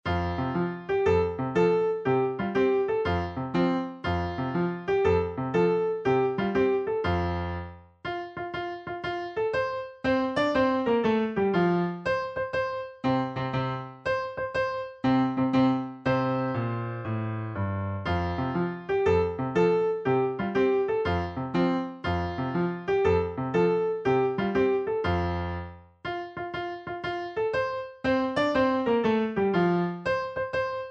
Piano Solo
Downloadable Instrumental Track